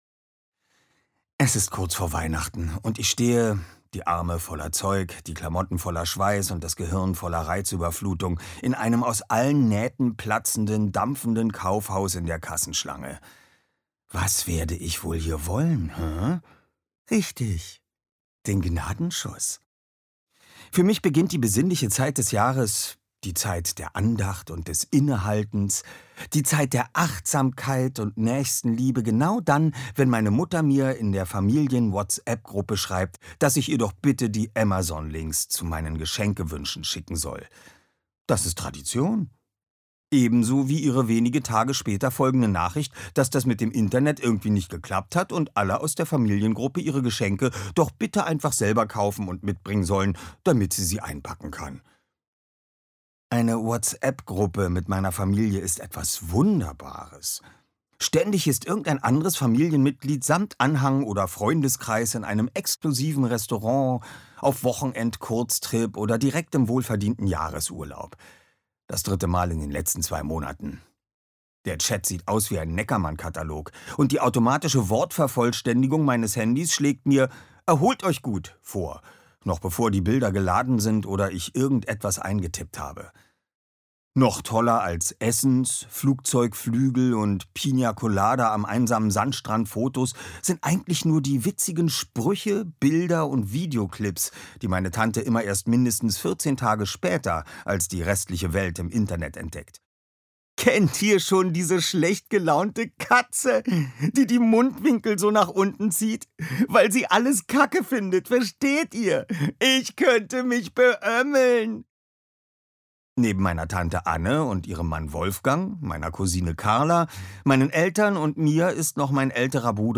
Ungekürzt.
Oliver Rohrbeck (Sprecher)